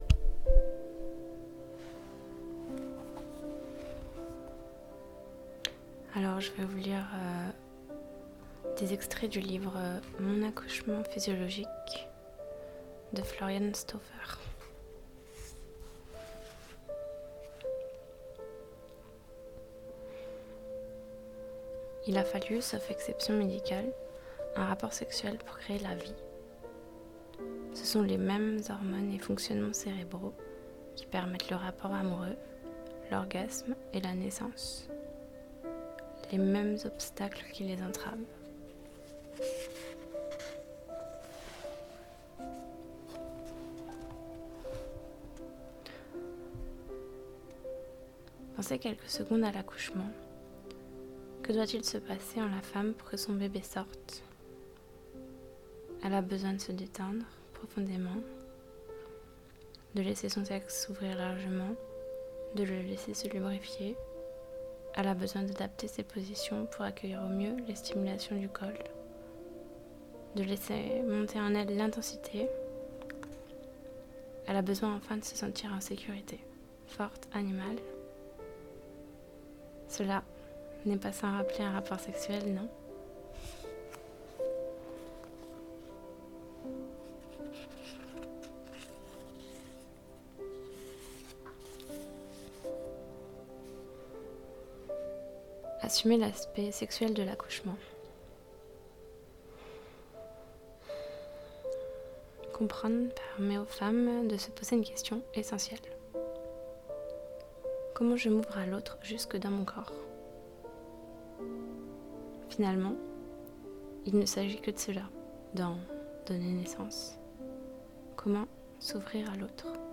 Je vous lis aujourd’hui un extrait du livre « Mon accouchement physiologique » de Floriane STAUFFER.